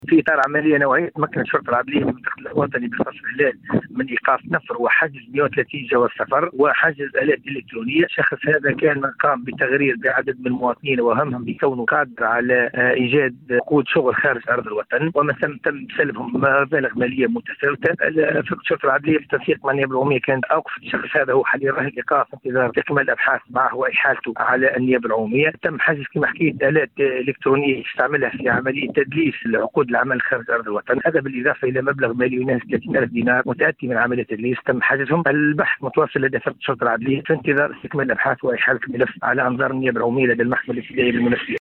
في تصريح ل”ام اف ام”